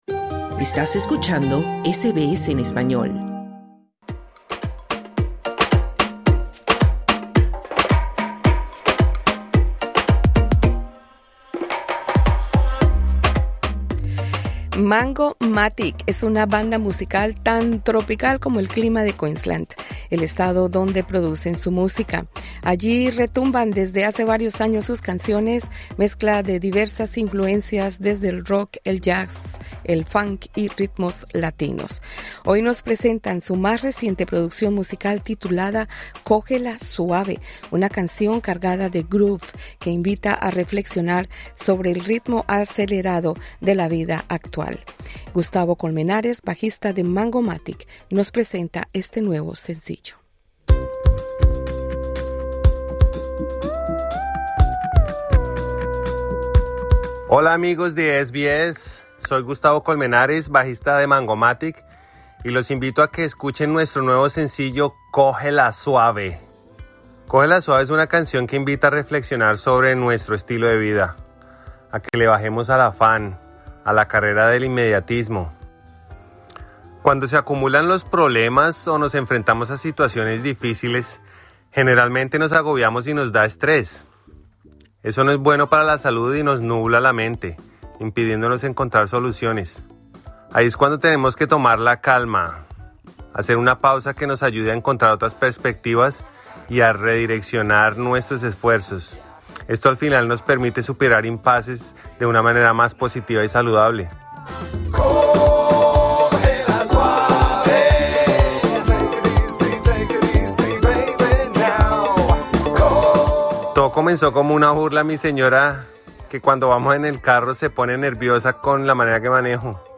Con frases de metales pegajosas y el groove que los caracteriza, el tema resuena tanto en la pista de baile como en la mente de quien lo escucha.